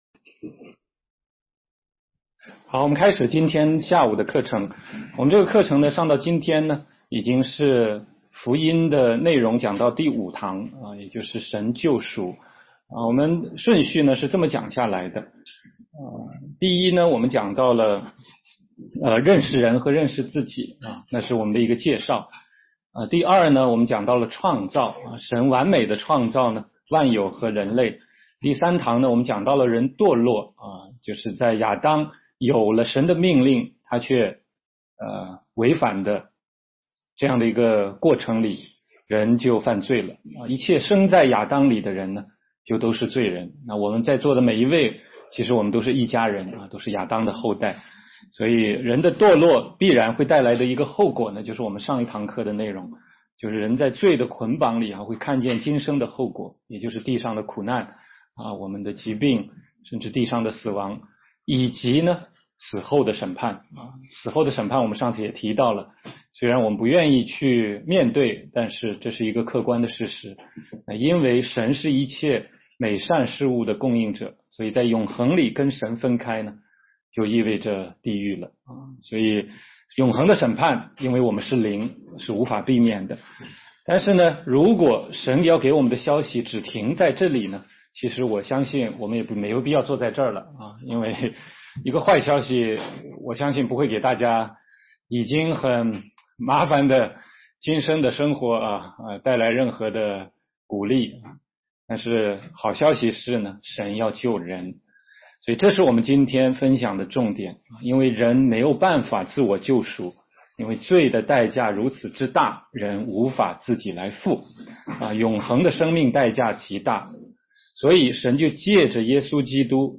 16街讲道录音 - 得救的福音第五讲：神救赎